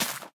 sapling_place2.ogg